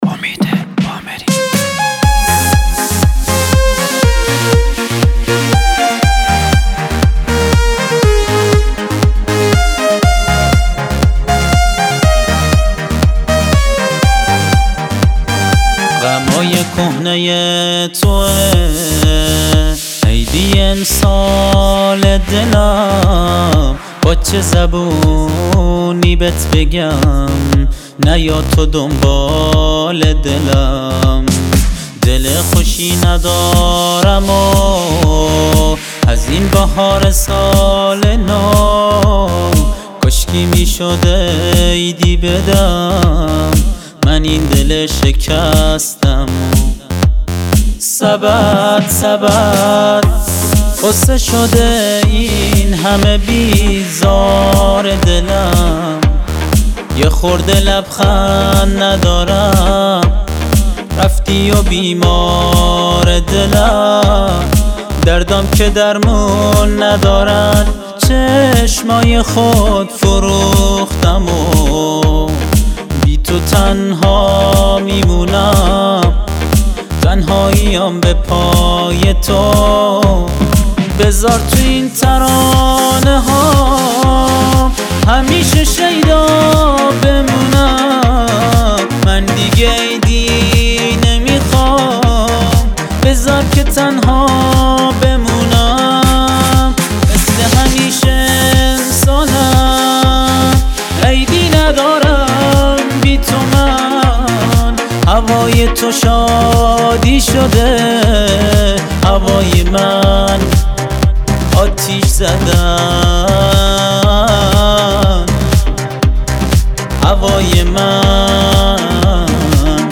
دانلود اهنگ بلوچی